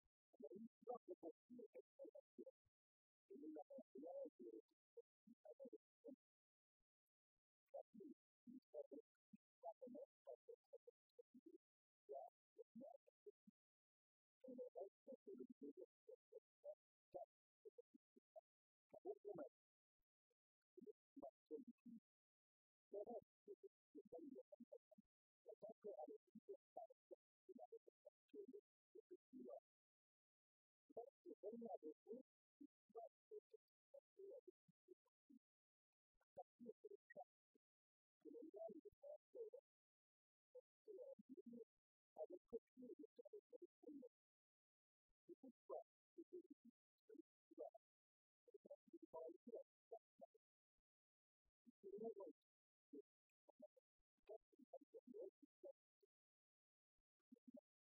Exposé sur les Guerre de Vendée
Catégorie Témoignage